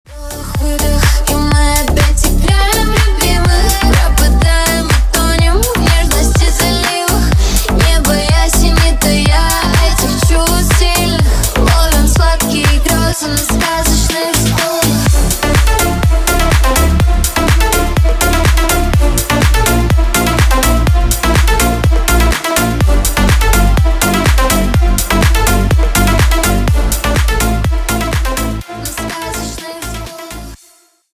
на русском клубные